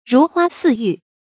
如花似玉 rú huā sì yù
如花似玉发音
成语注音ㄖㄨˊ ㄏㄨㄚ ㄙㄧˋ ㄧㄩˋ